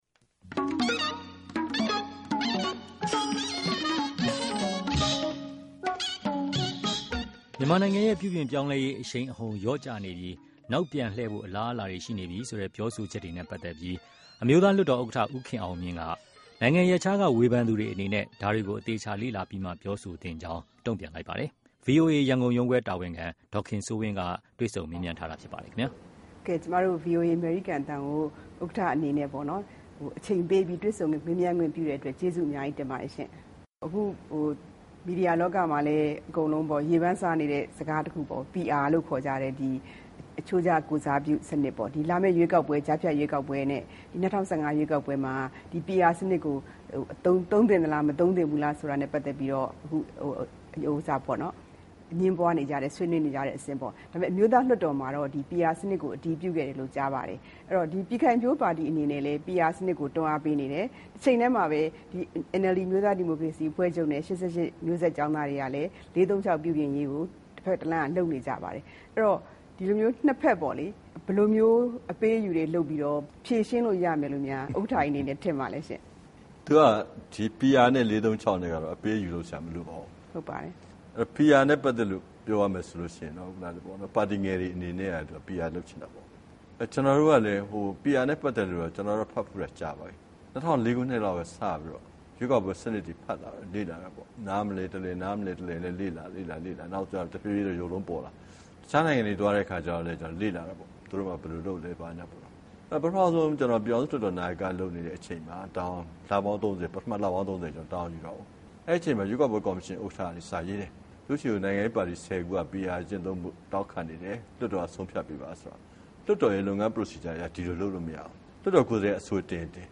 တွေ့ဆုံမေးမြန်းခန်း